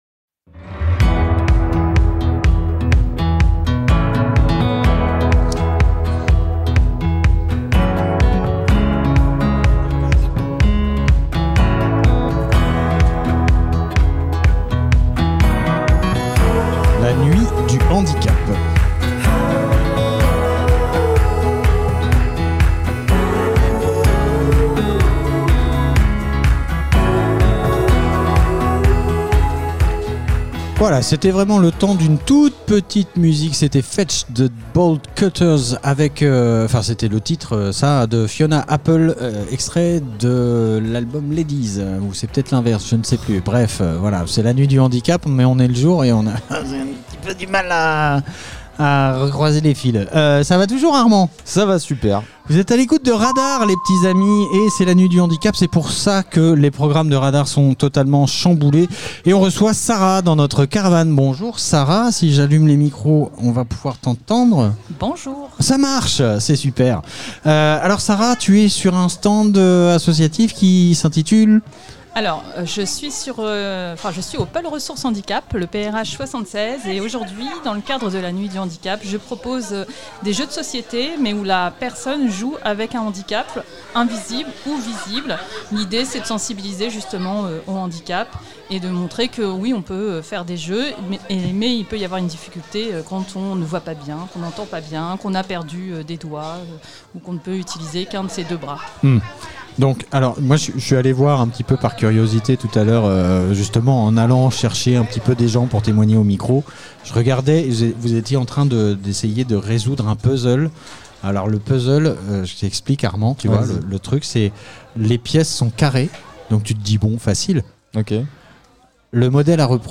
La nuit du handicap événement Interview handicap fécamp ville de fécamp nuit ville Nuit du Handicap